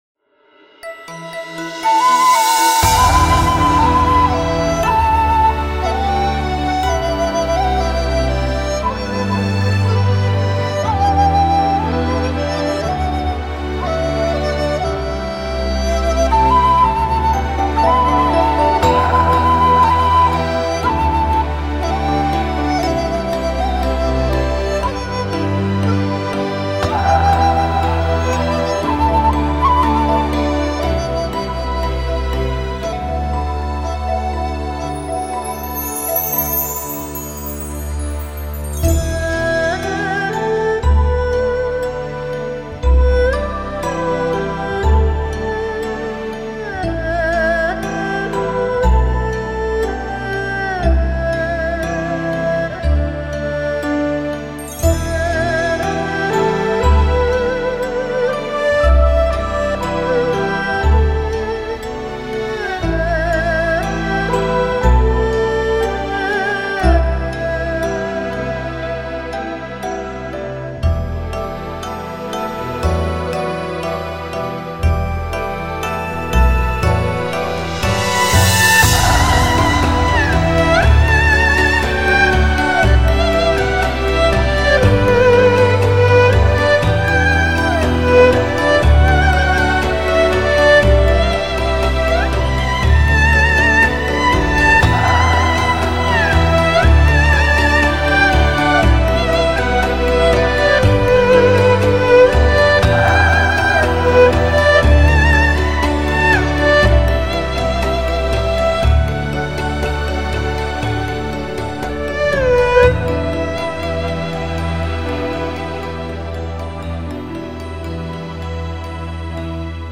但是里面的音乐却很有中国古典味